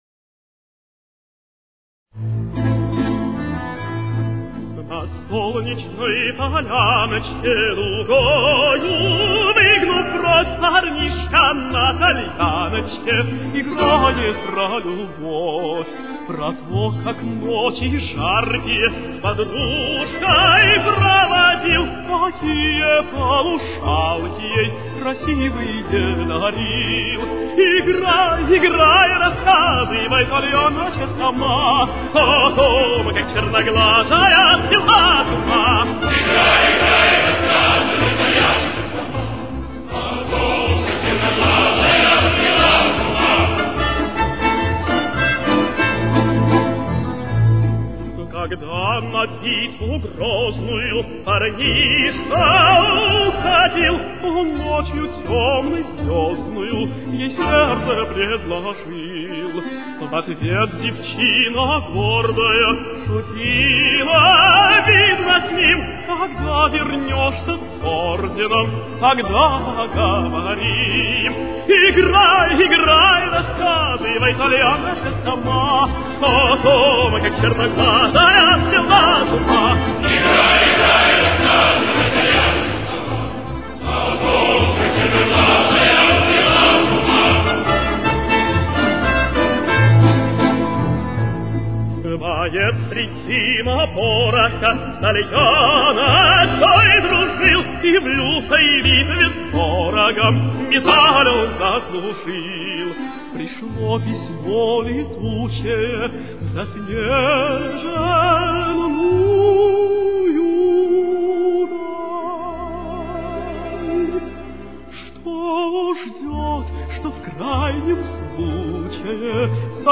Ля минор. Темп: 110.